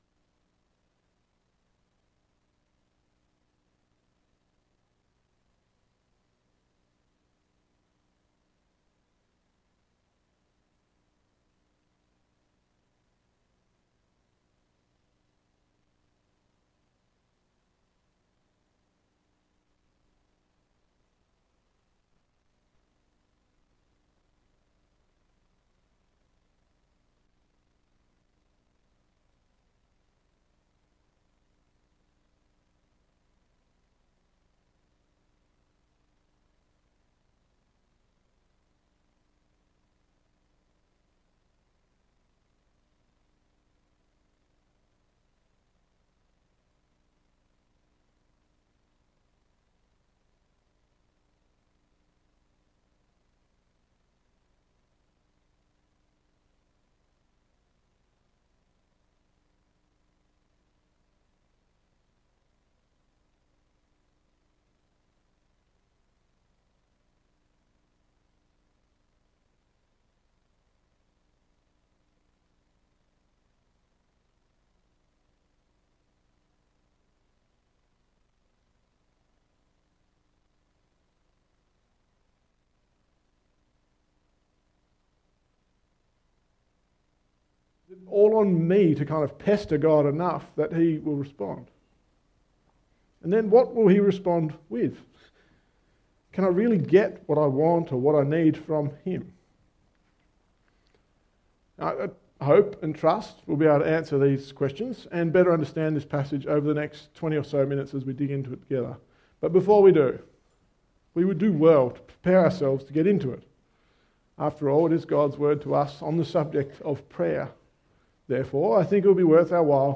Sermon Series: Sermon on the Mount